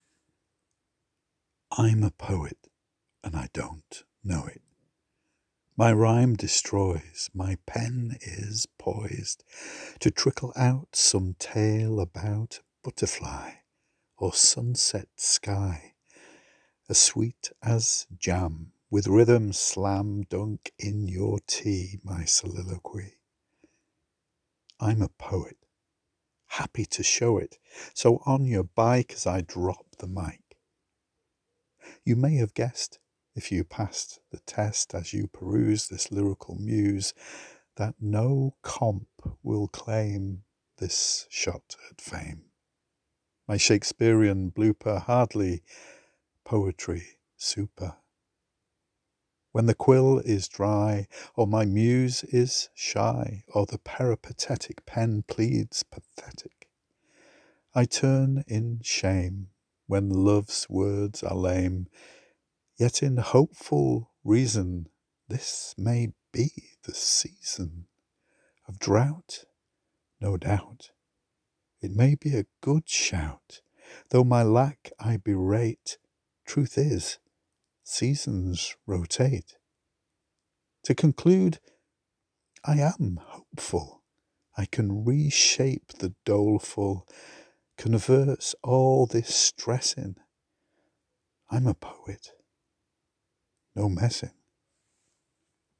Love your voice and particularly how you varied the pace, putting breaks in unexpected places.
Your reading brings it to life and adds that tongue in cheek quality that brings a smile as you listen.
Beautiful smooth voice, both hopeful & doleful.